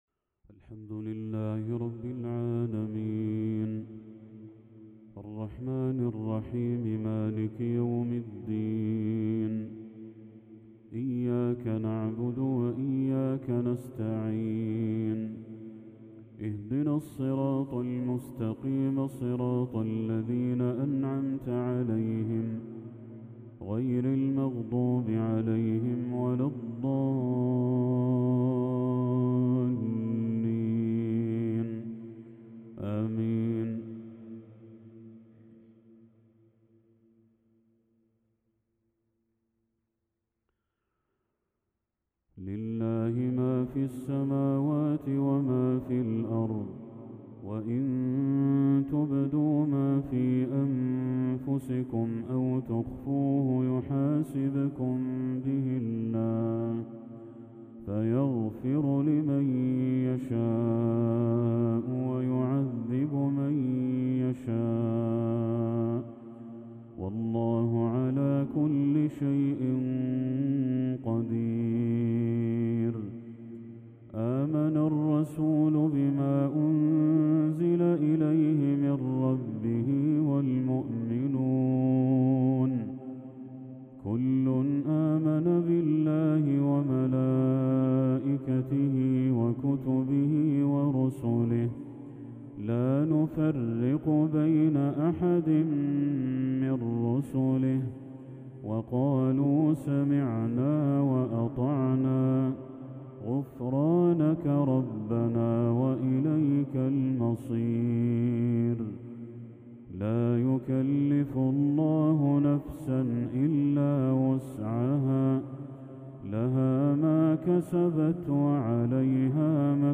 تلاوة إبداعية للشيخ بدر التركي خواتيم سورتي البقرة والشورى | عشاء 27 ذو الحجة 1445هـ > 1445هـ > تلاوات الشيخ بدر التركي > المزيد - تلاوات الحرمين